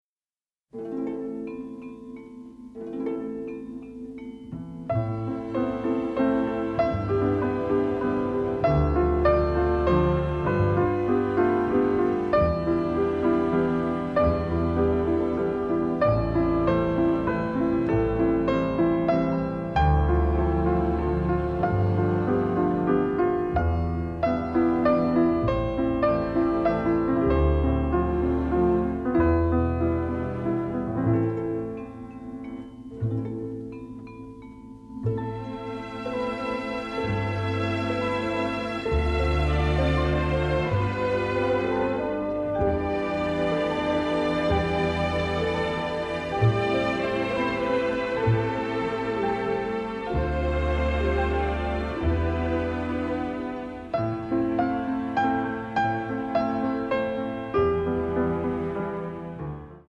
Romantic and melancholic